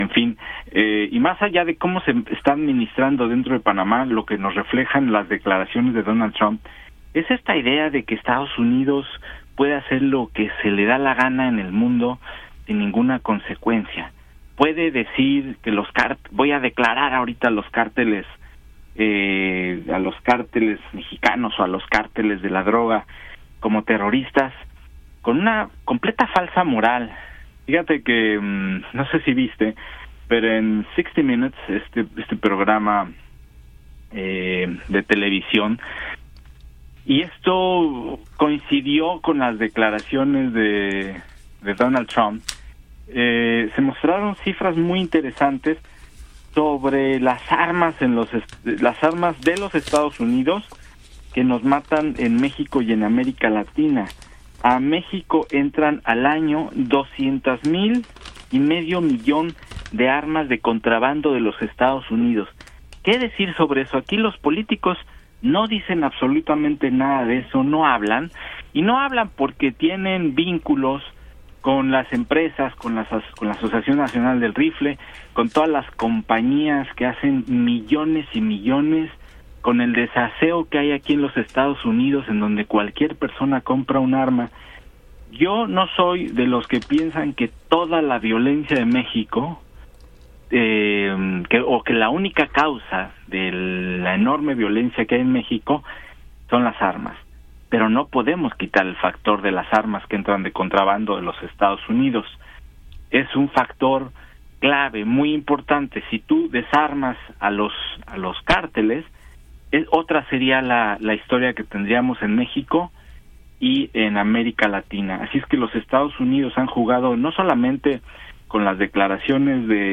entrevista
durante el programa de radio Península 360 Press en Hecho en California